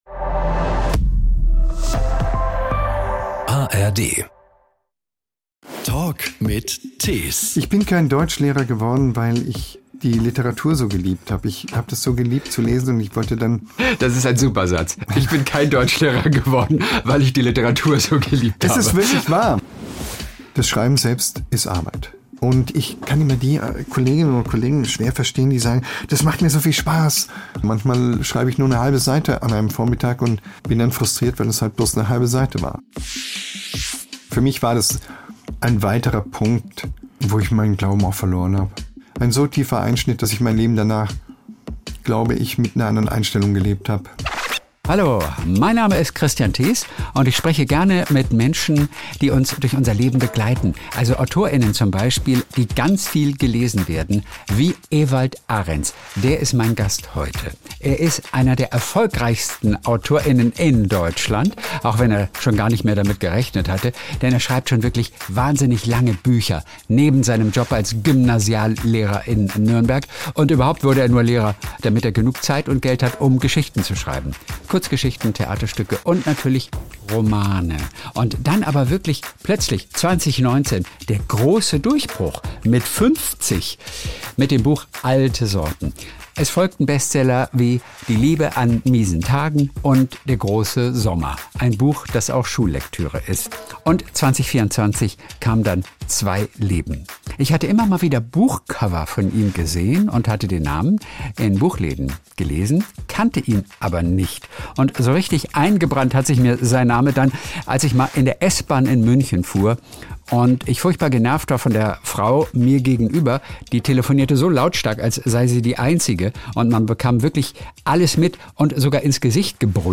Im angenehmen Gespräch wurde auch über unangenehme Dinge gesprochen, wie den Tod und wann er als Pfarrerssohn den Glauben verloren hat. Außerdem erfahrt ihr, warum er lieber einen Salto vom Fünfer macht als vom Dreier und warum er gerne stilvoll angezogen ist.